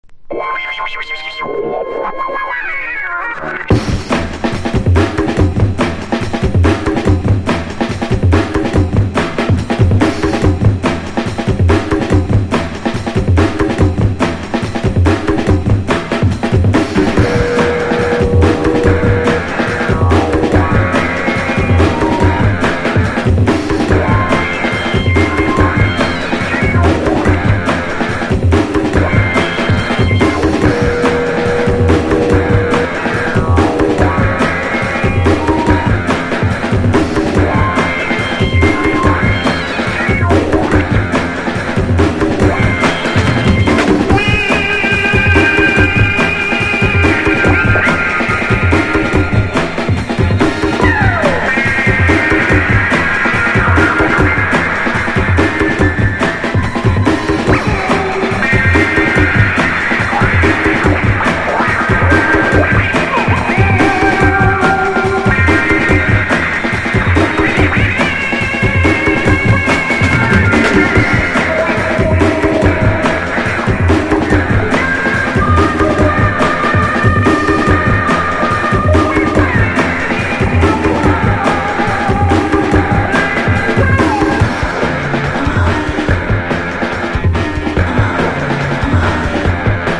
HIPHOPとBREAK BEATSとROCKが絶妙なバランスで交じり合った名作。